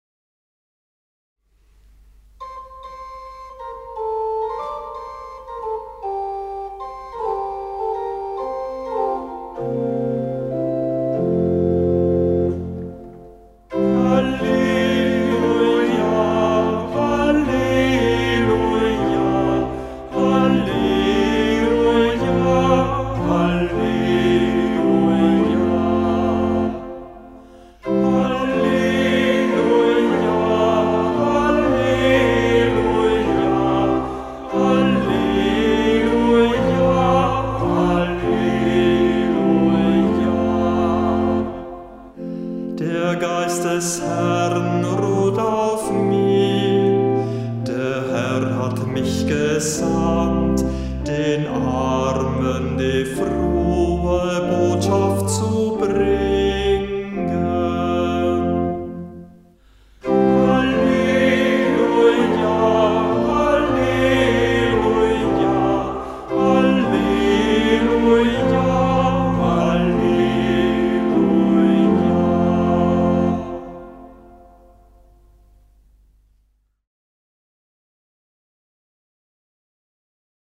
Ruf vor dem Evangelium - Juli 2024